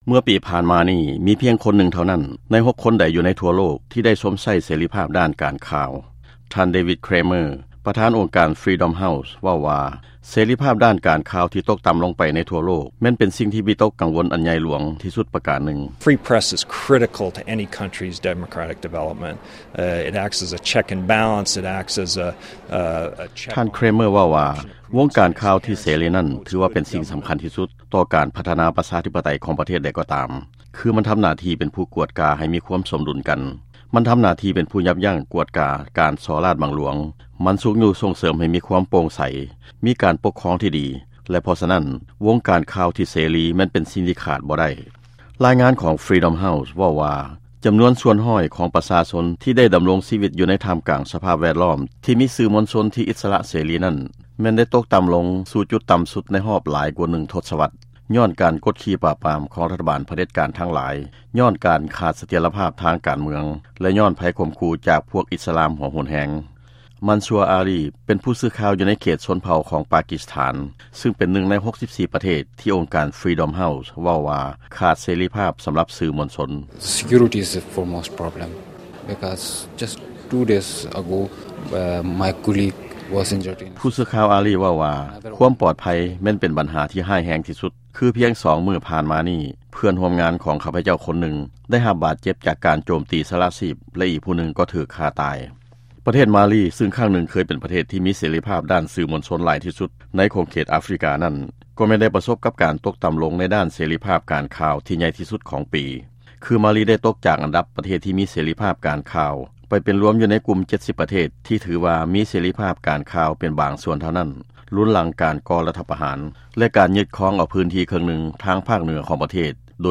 ຟັງລາຍງານ ເສລີພາບການຂ່າວທົ່ວໂລກຕົກຕໍ່າ